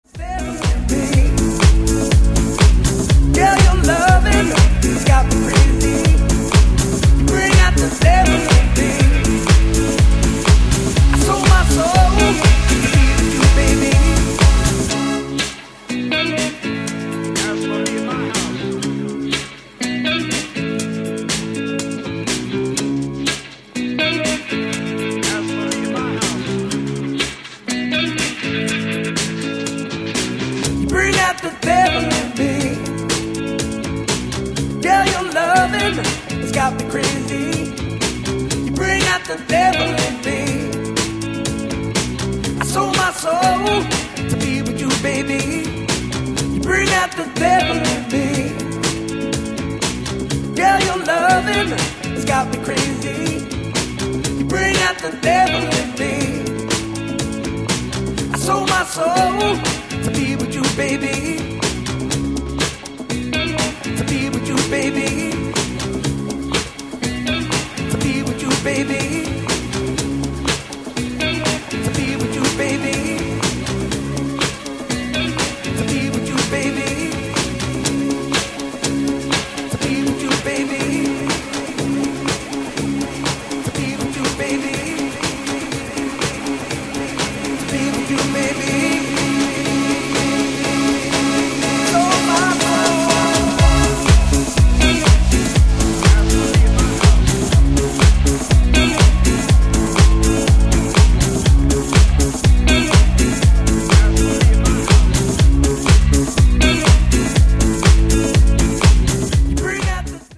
ジャンル(スタイル) HOUSE / DEEP HOUSE / DISCO HOUSE